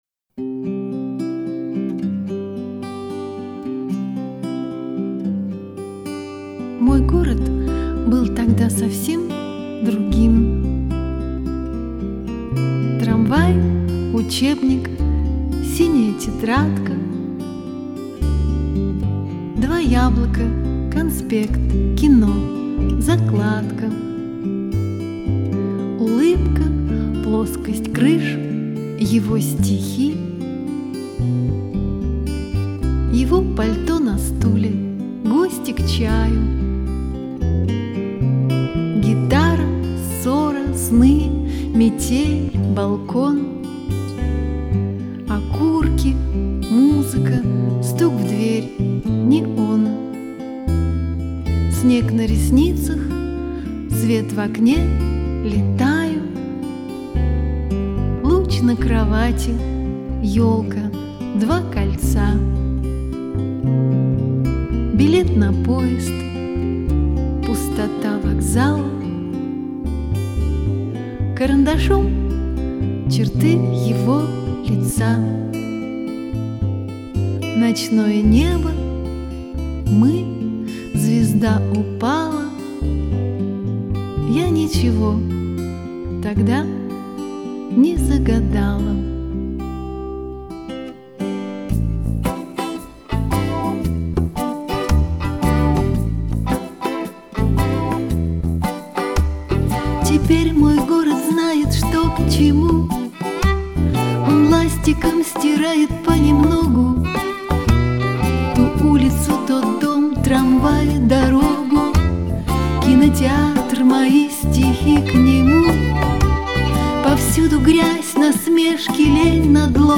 играющая в стиле "Сенти-Ментальный рок".
гитары, клавишные, перкуссия, сэмплы
скрипка
аккордеон
бас-гитара